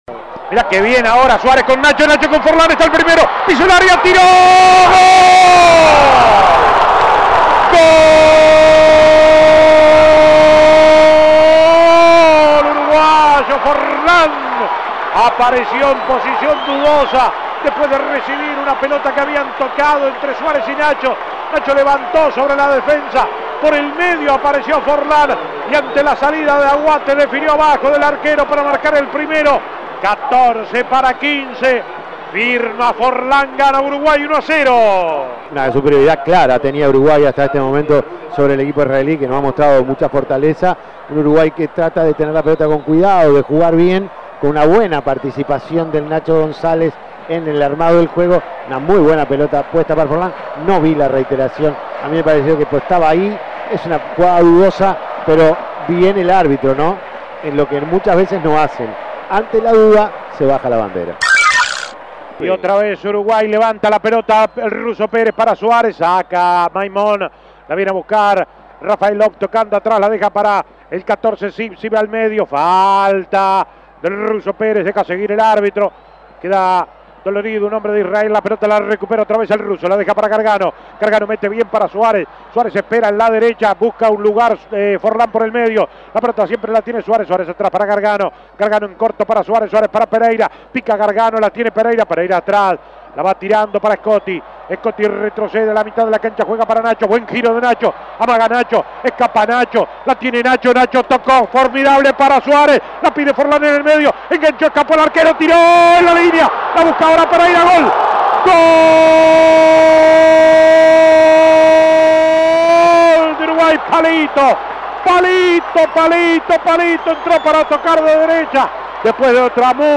Goles y comentarios Escuche los goles del partido Uruguay-Israel Imprimir A- A A+ Uruguay se despidió de la gente goleando 4-1 a Israel.